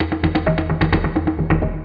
tzwdrum3.mp3